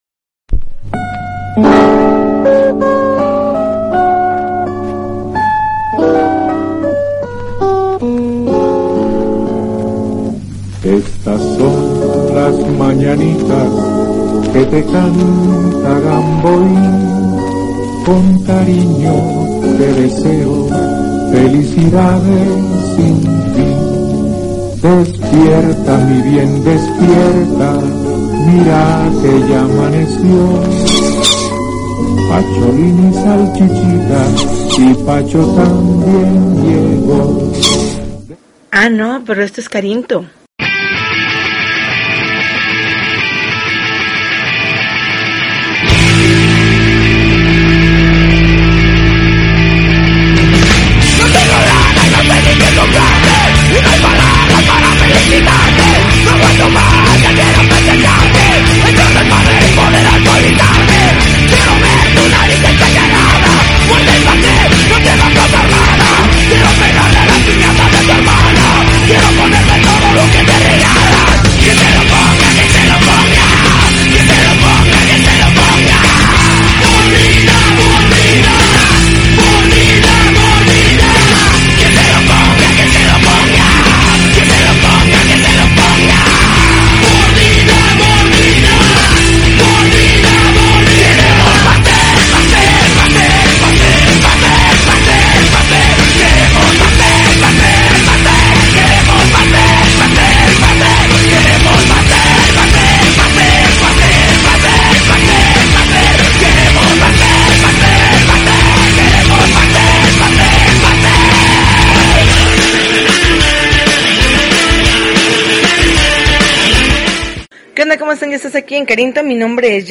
January 22, 2013Podcast, Punk Rock Alternativo